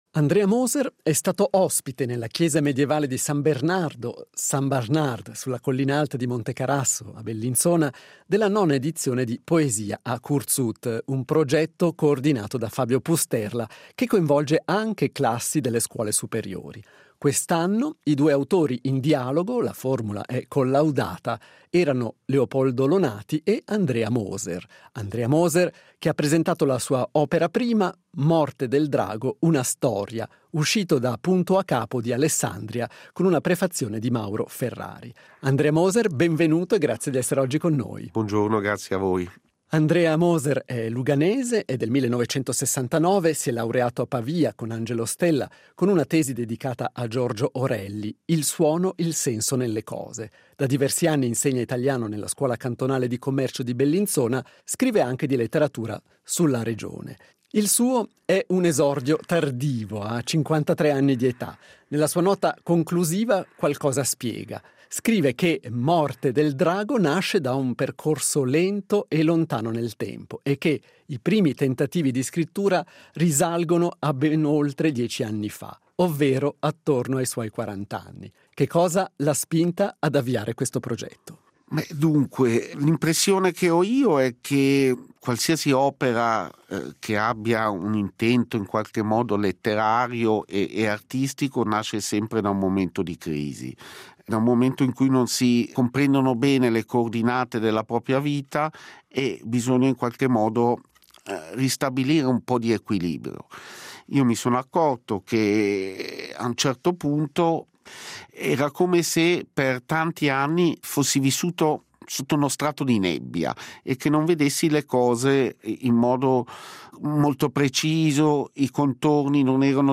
Presentazione del libro
Intervista integrale.